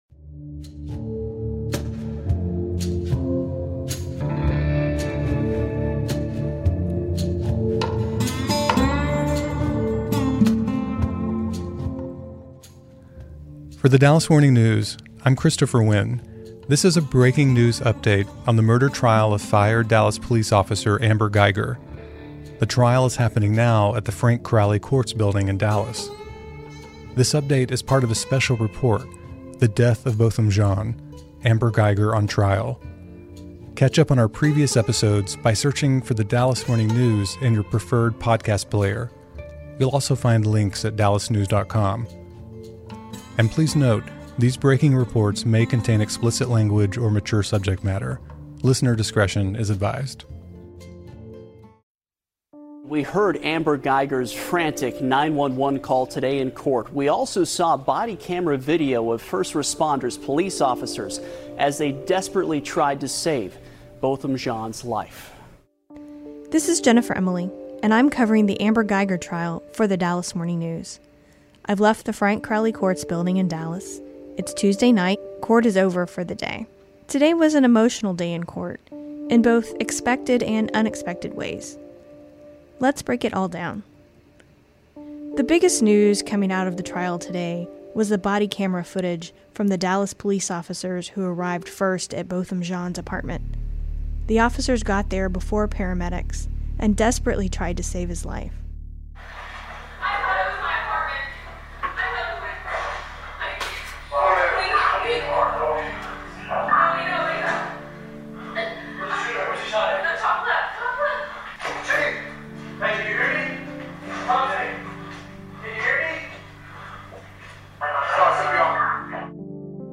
The Dallas Morning News crime reporters discuss current events in the courts, with the police departments, and on the streets of North Texas.